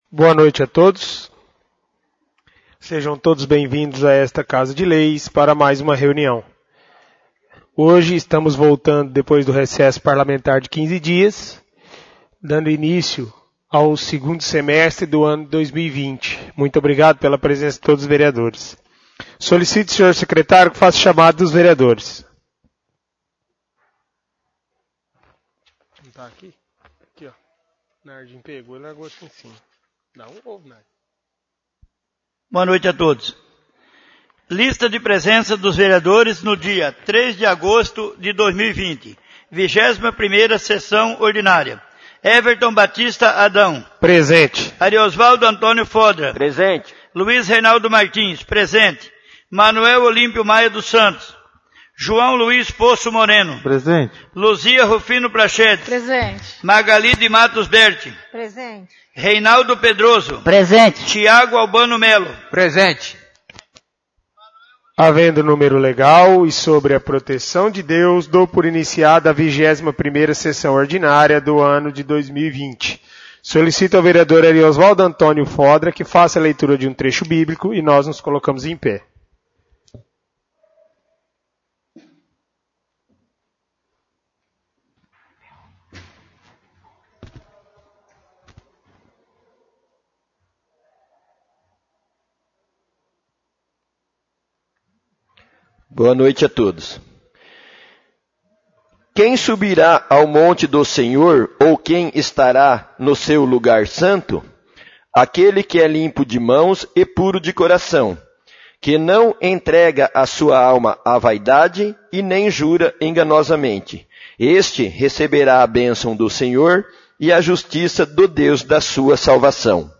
21° Sessão Ordinária